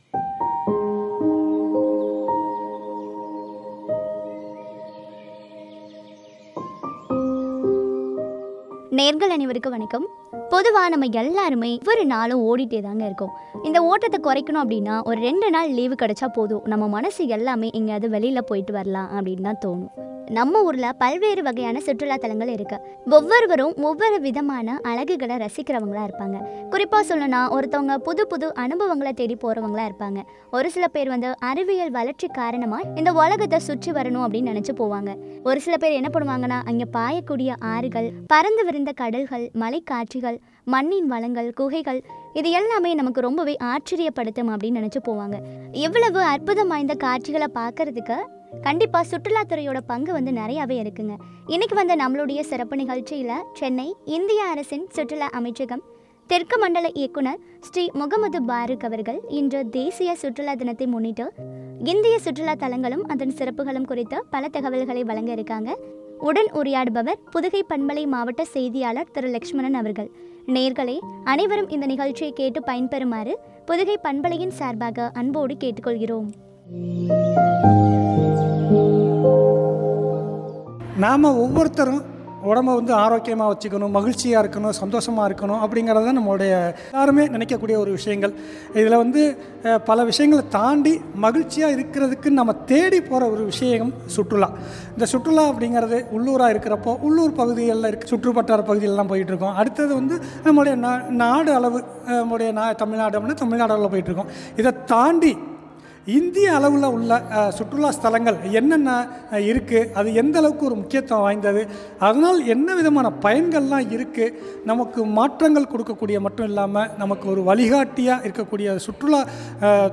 சிறப்புகளும் பற்றிய உரையாடல்.